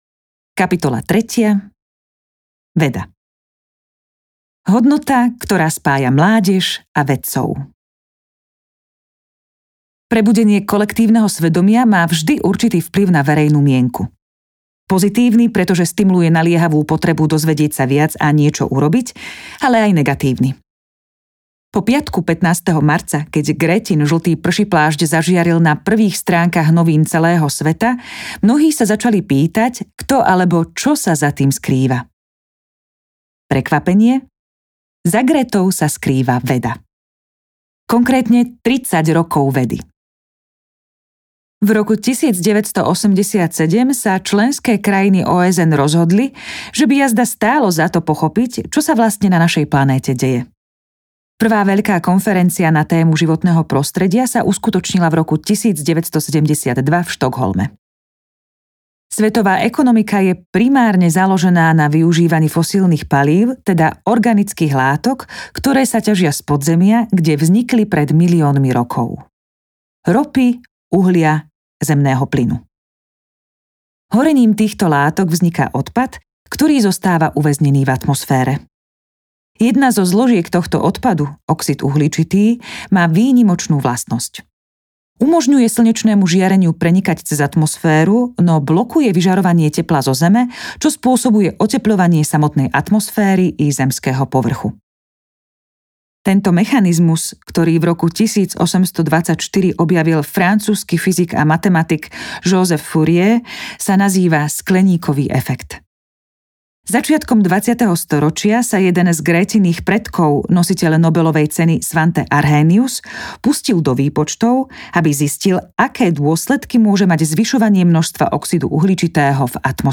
Volám sa Greta audiokniha
Ukázka z knihy